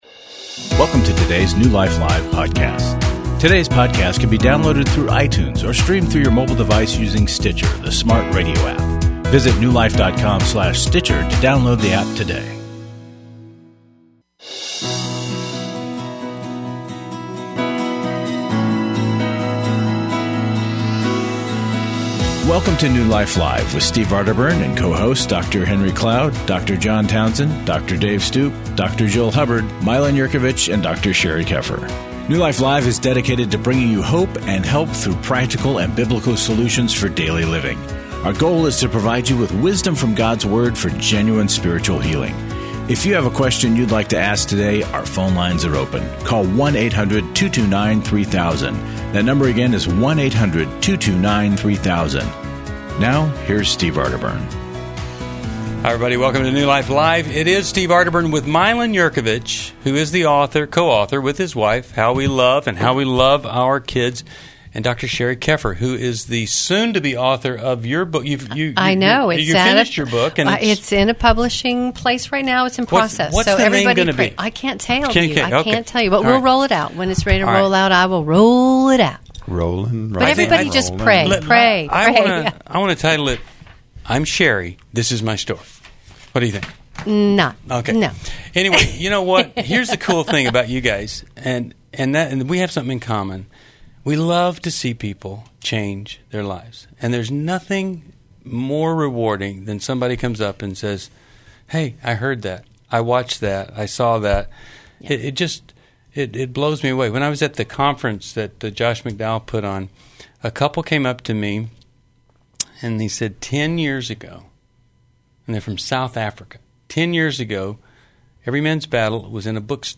Discover insights on communication, reconciliation, and emotional healing as hosts tackle caller concerns about relationships and grief.